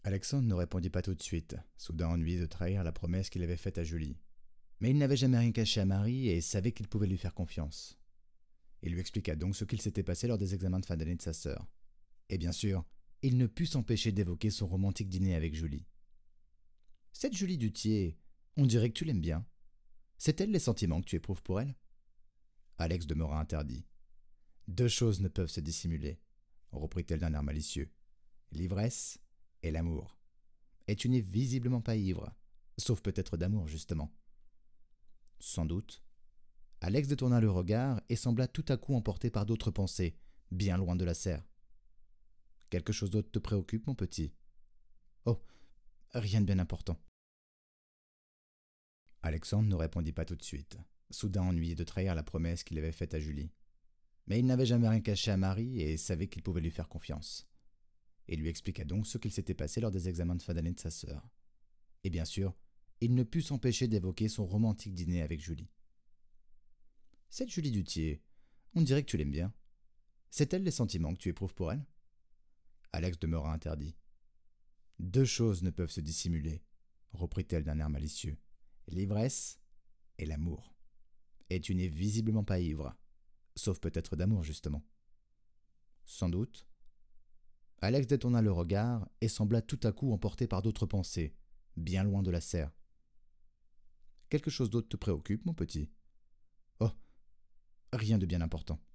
Voix off
J'ai une voix médium/grave.
27 - 69 ans - Baryton